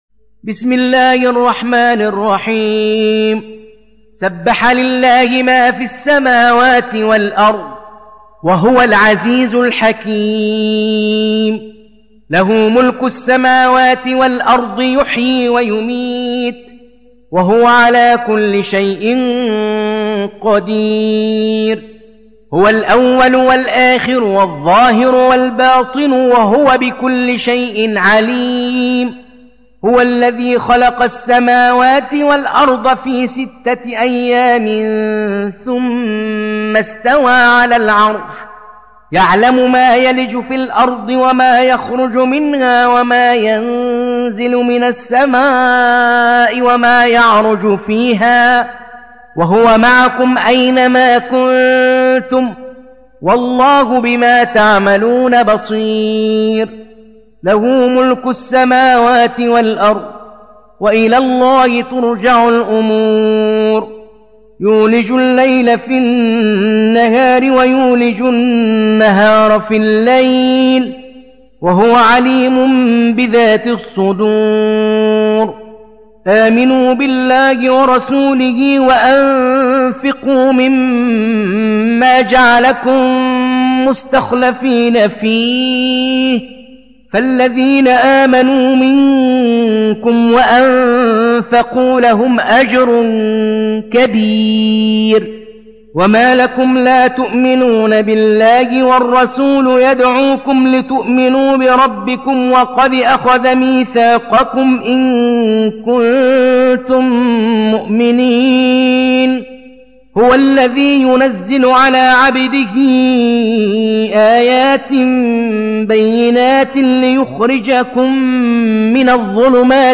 (روایت حفص)